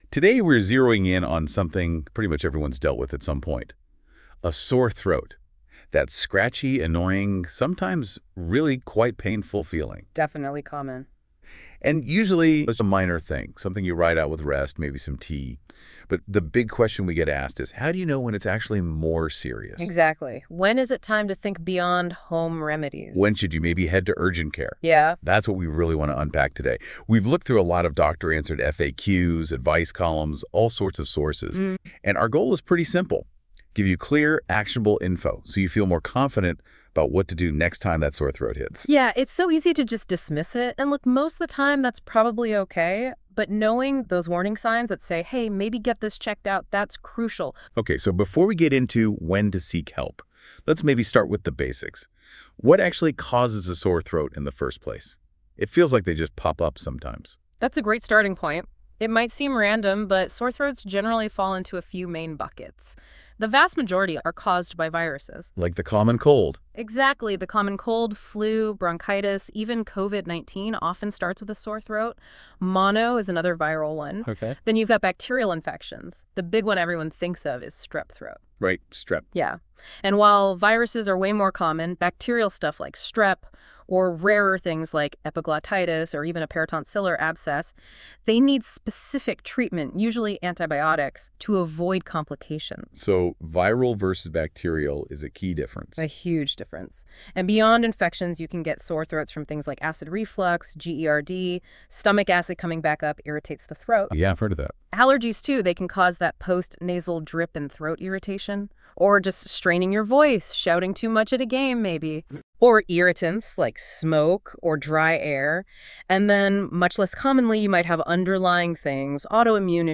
Listen to a discussion on getting help for a sore throat